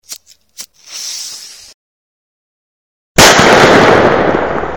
smallfirework.mp3